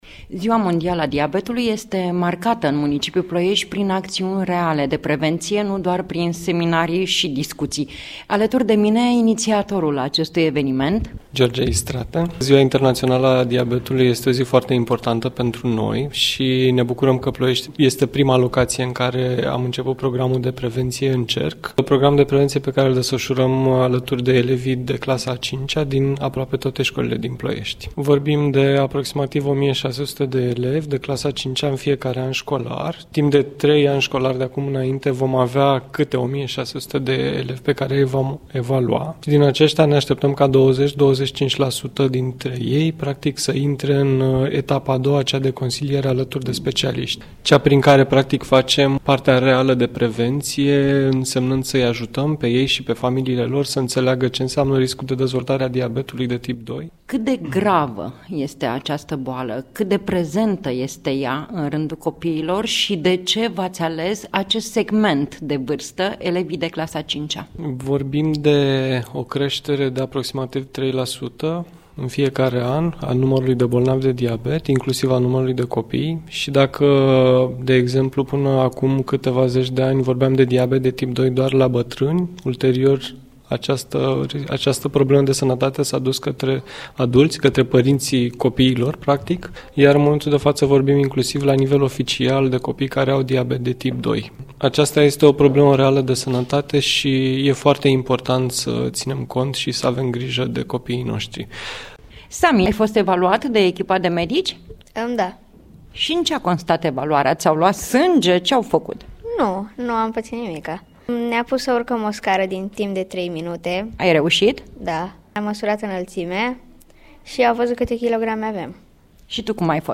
Reportaj radio difuzat la Radio România Actualități în cadrul emisiunilor „Obiectiv România”, „La dispoziția dumneavoastră” și în cadrul jurnalului orei 08:00, în data de 14 noiembrie 2017, cu ocazia Zilei Mondiale a Diabetului.